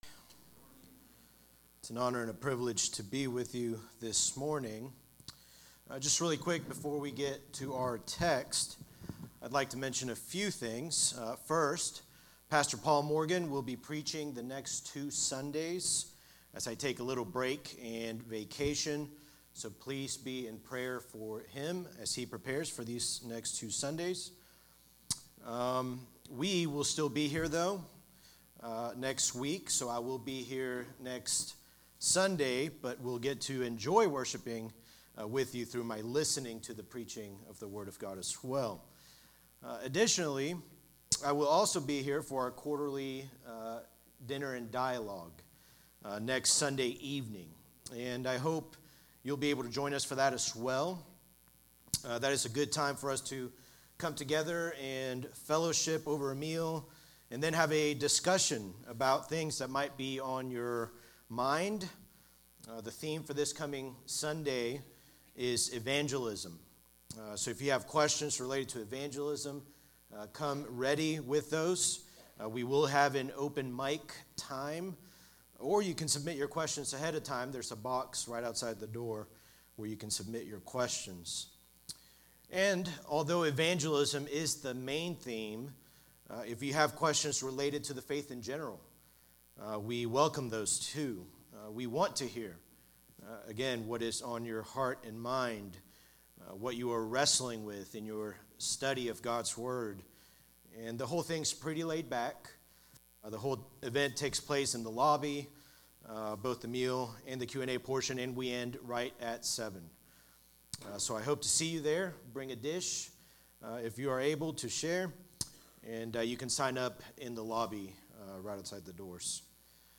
Sermons by Eatonville Baptist Church EBC